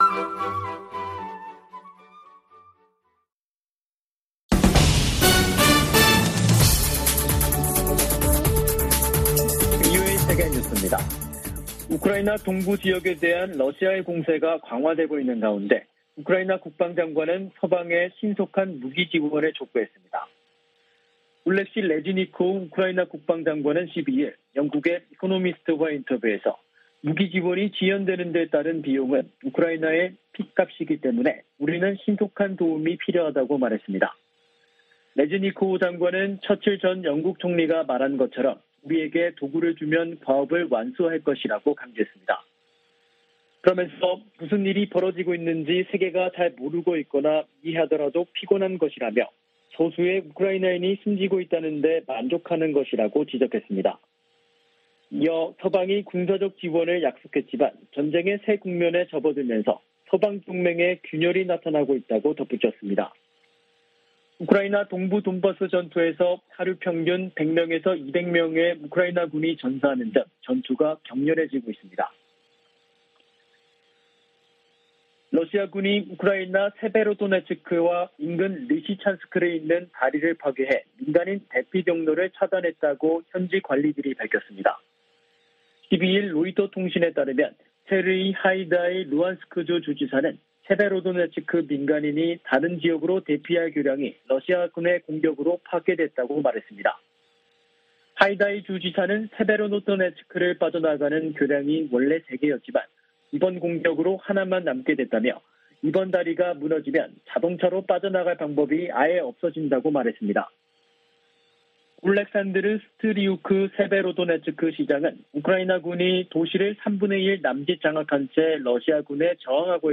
VOA 한국어 간판 뉴스 프로그램 '뉴스 투데이', 2022년 6월 13일 2부 방송입니다. 김정은 북한 국무위원장이 10일 "대적 강대강 정면투쟁"의 강경기조를 천명한데 이어, 12일 북한이 방사포 무력시위를 벌였습니다. 미국과 일본, 호주 국방장관들이 북한의 핵무기 개발과 거듭된 미사일 발사를 강력 비판했습니다. 영국 국제전략문제연구소(IISS)는 미국과 한국의 대북 영향력은 갈수록 줄어드는 반면 중국의 영향력을 커지고 있다고 진단했습니다.